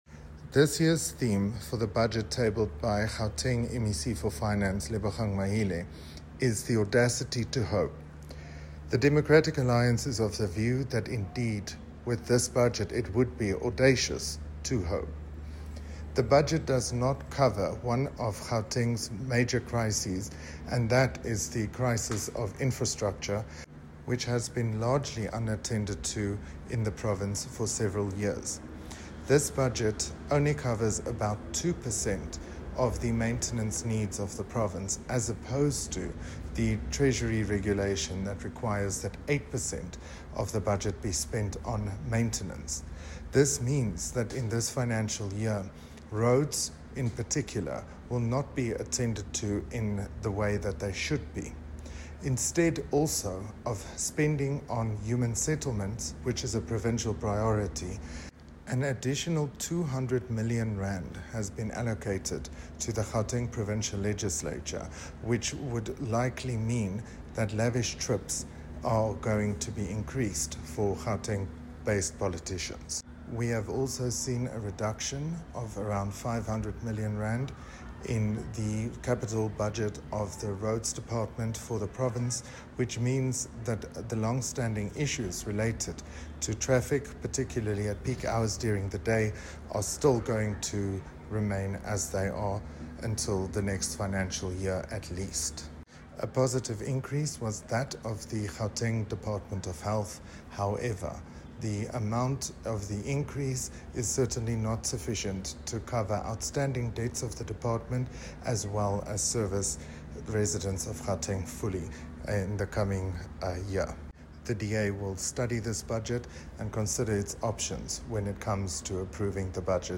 soundbite by Ruhan Robinson MPL.